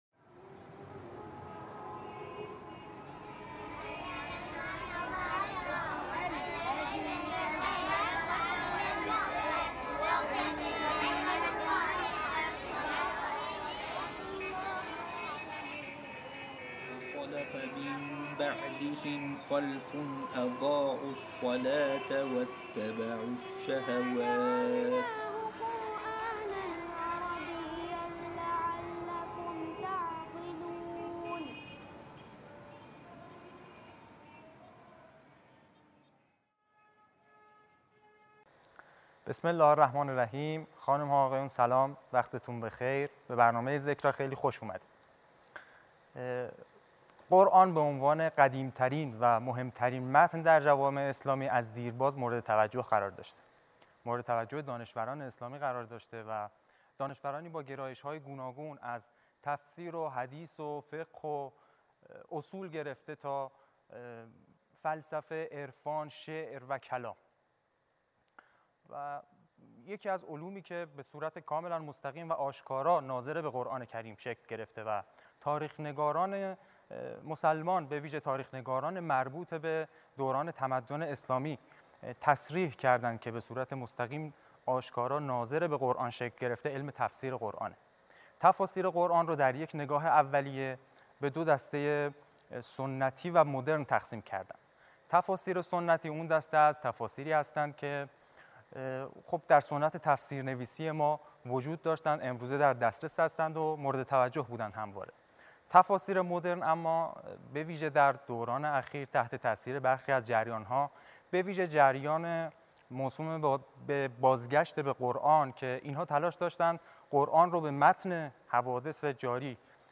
گفتگو با پروفسور سید حسین نصر